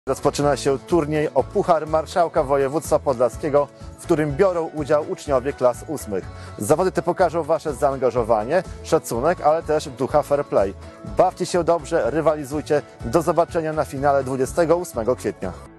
Młodych piłkarzy do rywalizacji zachęca marszałek województwa podlaskiego Łukasz Prokorym.